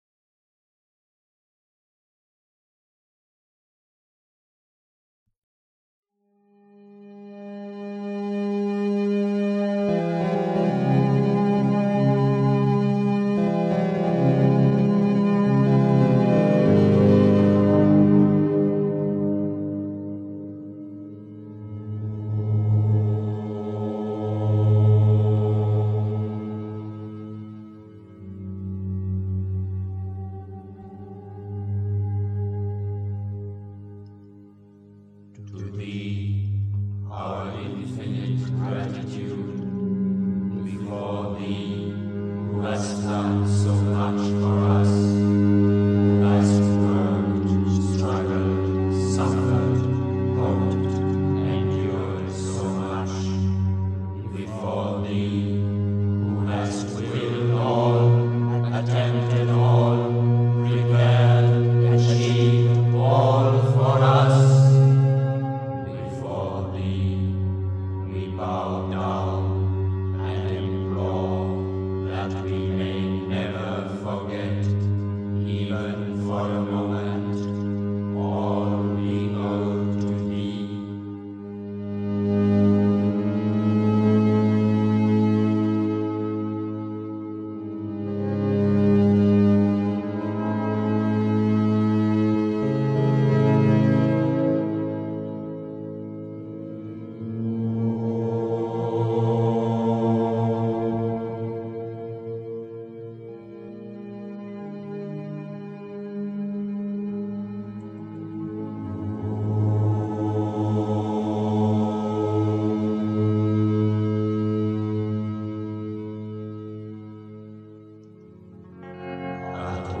Unglücklichsein und Unaufrichtigkeit (Die Muter, White Roses, 5 April 1966) 3. Zwölf Minuten Stille.